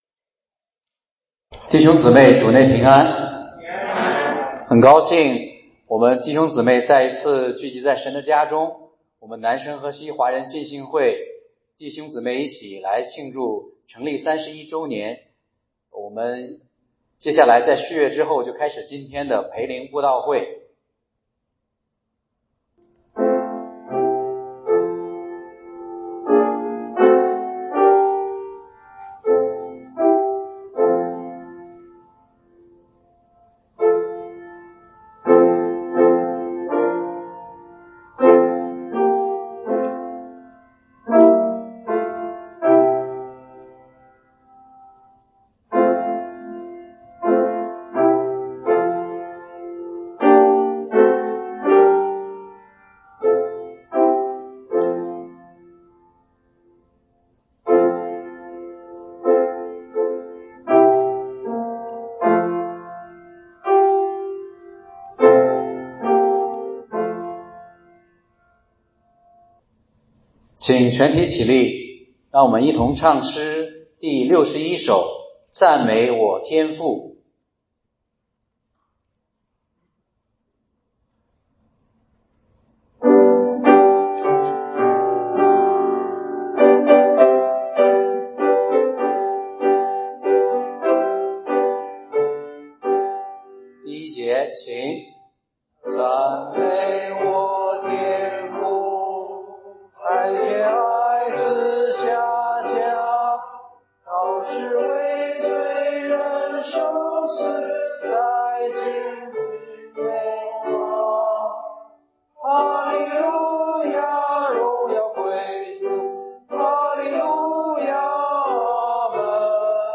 合神心意的大卫 （培灵会）